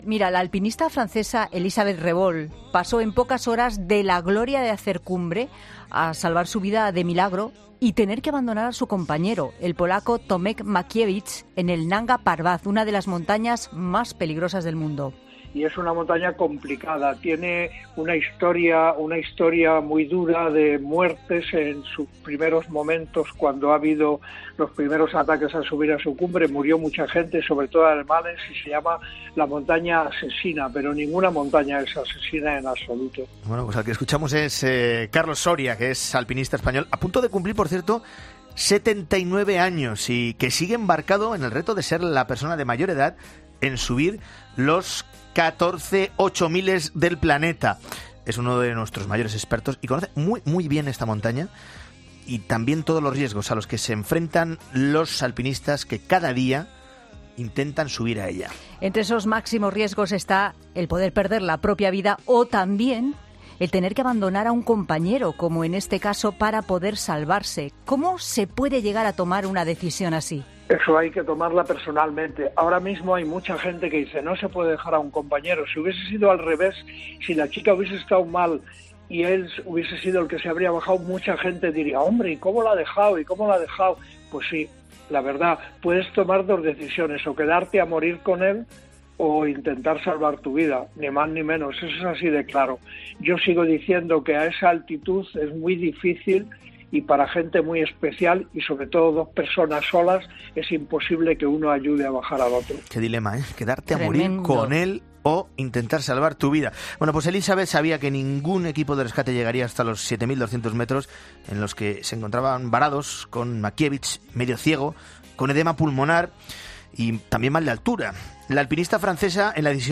Es la reacción de Carlos Soria, alpinista español que intenta alcanzar el reto de subir los catorce ochomiles, tras perderse el rastro de un montañero polaco en el Nanga Parbat (Himalaya).